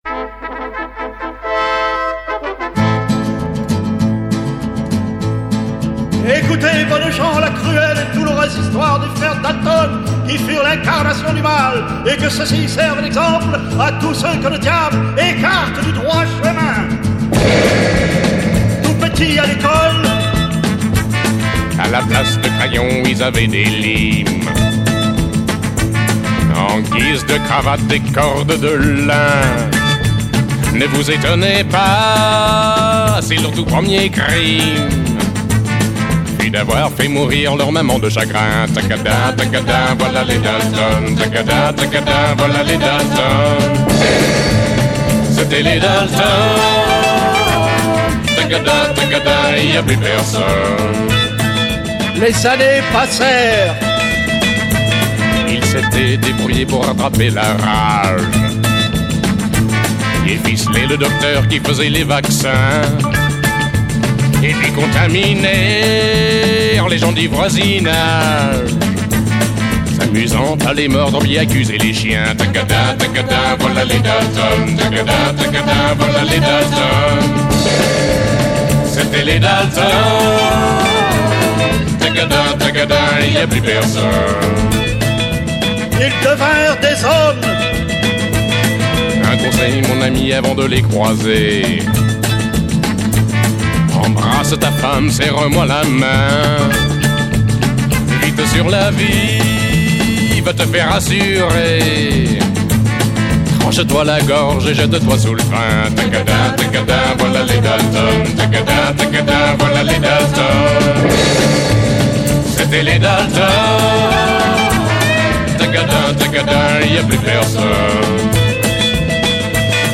Chanson, Pop, Folk, Jazz